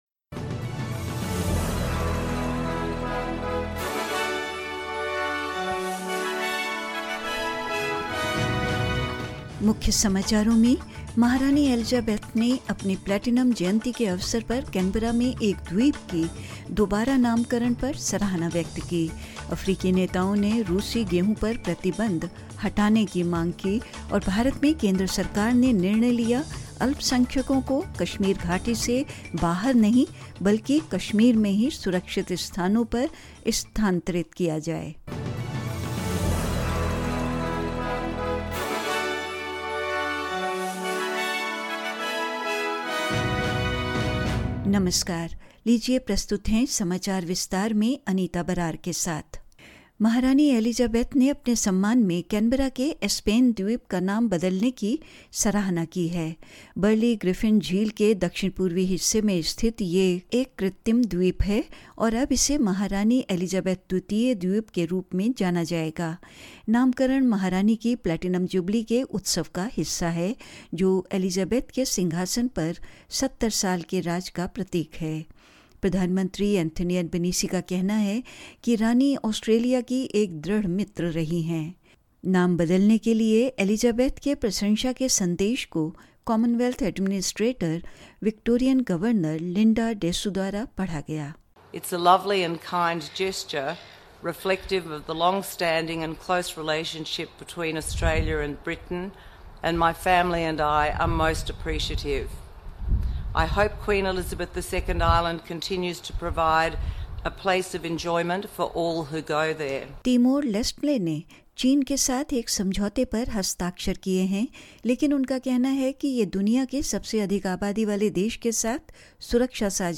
In this latest SBS Hindi bulletin: Queen Elizabeth expresses appreciation for the re-naming of an island in Canberra to mark her Platinum Jubilee; African leaders calls for sanctions on Russian wheat to be lifted; In India, the Centre is not in favour of moving minorities out of the Kashmir Valley instead would relocate and provide security there only and more news.